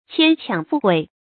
牵强附会 qiān qiǎng fù huì
牵强附会发音
成语正音强，不能读作“qiánɡ”。